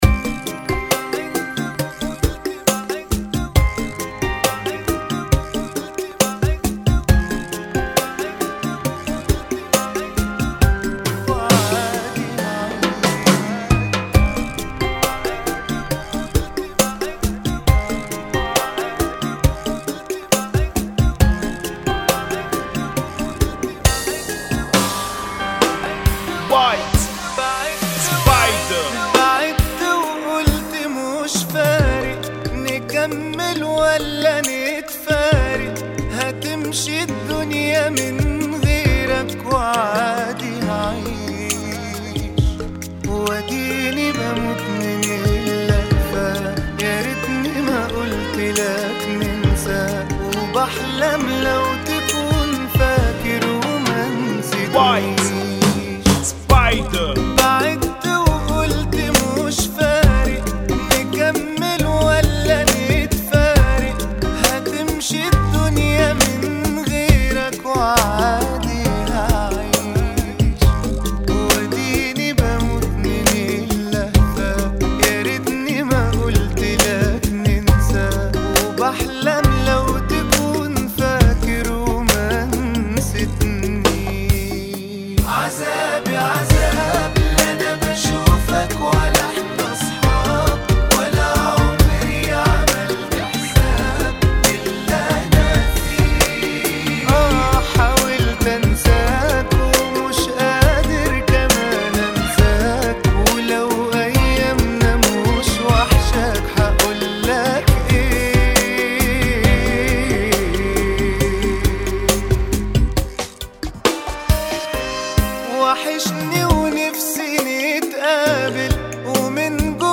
[ 68 Bpm ]
Funky